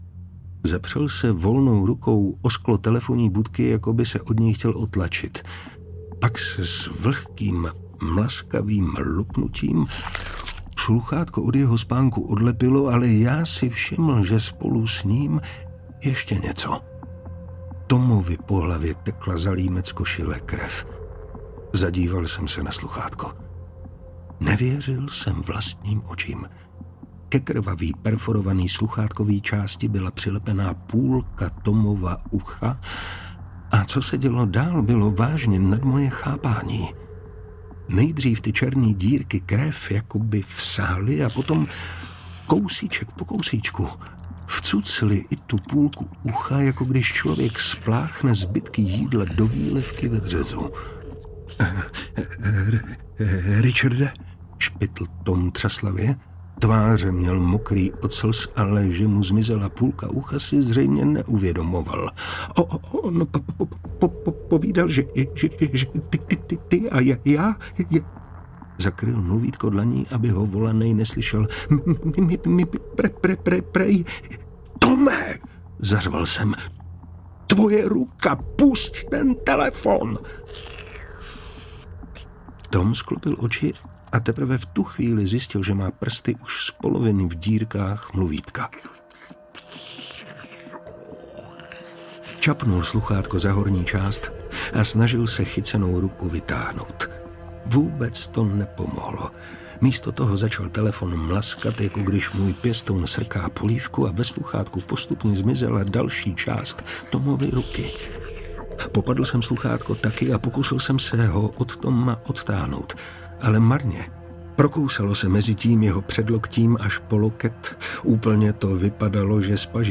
Audiobook
Read: Martin Preiss